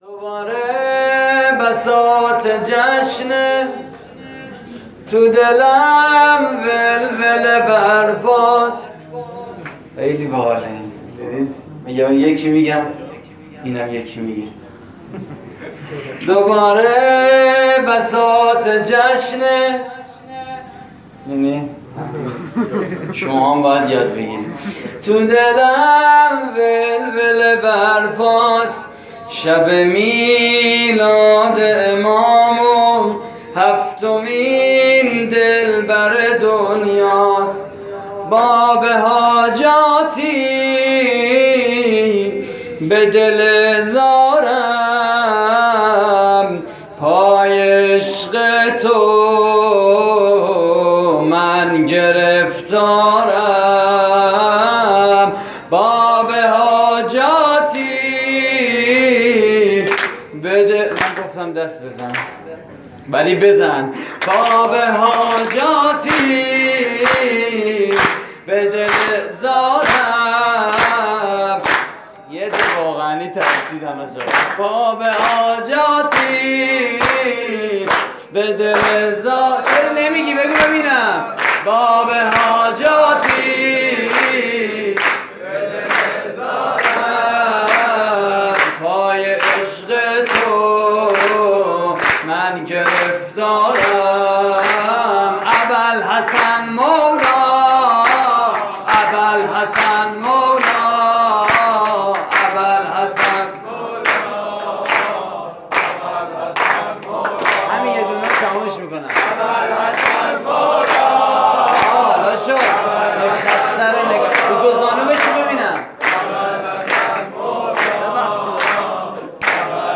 مراسم جشن ولادت امام موسی کاظم (ع) / هیئت کریم آل طاها (ع) - مشهد مقدس؛ 20 شهریور 96
سرود: باب حاجاتی به دل زارم؛ پخش آنلاین |